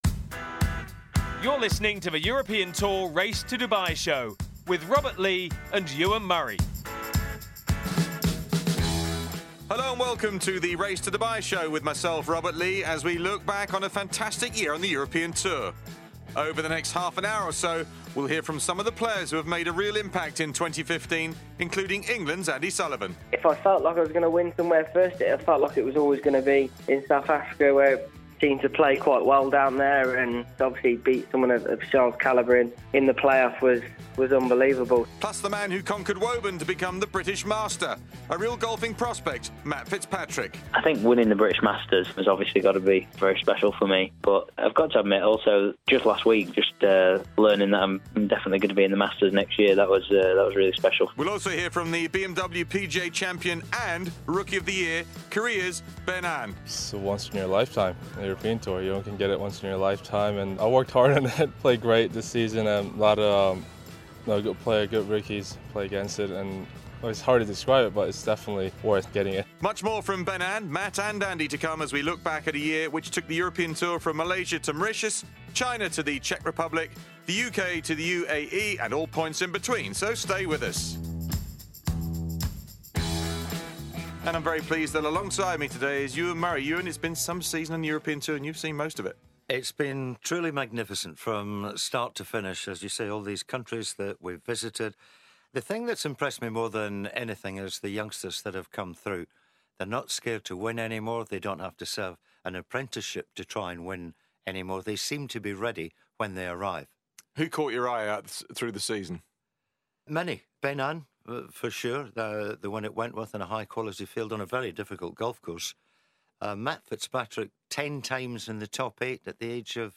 Robert Lee and Ewen Murray look back on a wonderful year on the European Tour. They speak exclusively to British Masters supported by Sky Sports champion Matt Fitzpatrick, three-time tournament winner Andy Sullivan and hear from the Sir Henry Cotton Rookie of the Year Byeong-Hun An.